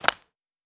Ball Hit Slap.wav